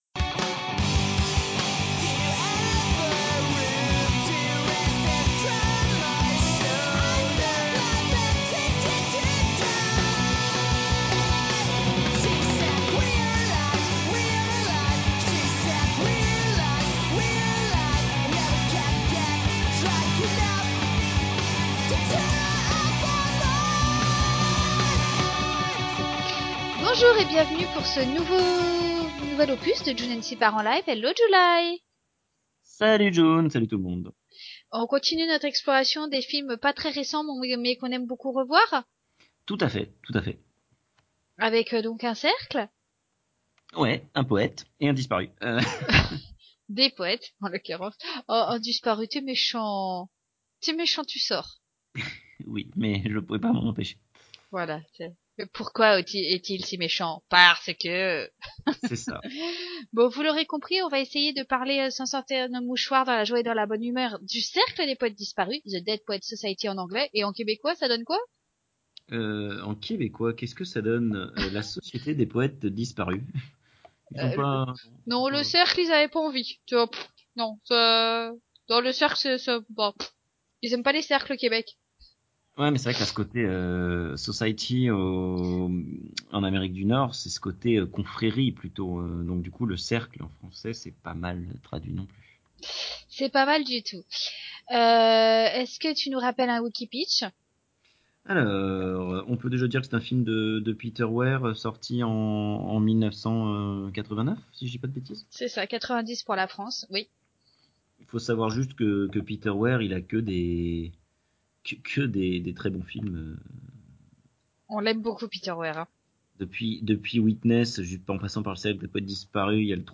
Je vous de m’excuser de ce désagrément, néanmoins la qualité ne devrait pas en souffrir, même si c’est, hélas, moins pratique.